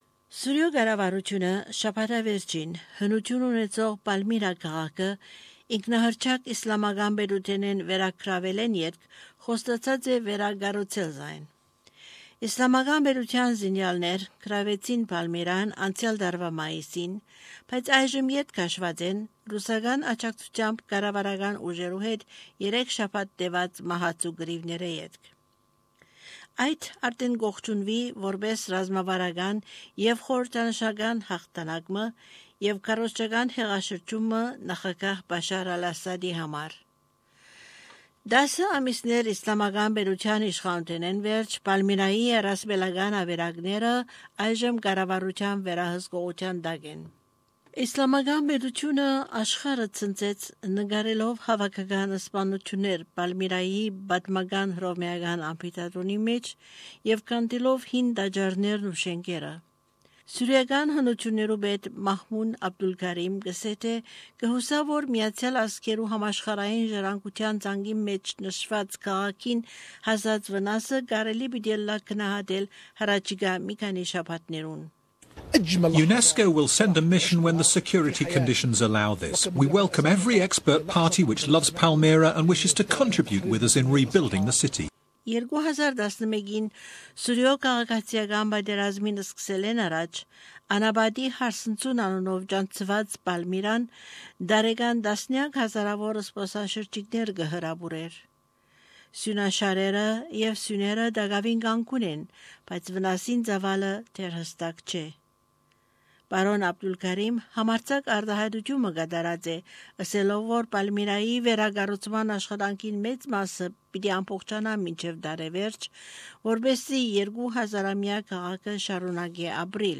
The Syrian government has promised to rebuild the ancient city of Palmyra (pal-MEER-uh) after recapturing it from the self-proclaimed Islamic State, or I-S, over the weekend. I-S fighters overran the city last May but have now pulled out after a deadly three-week battle with Russian-backed government forces.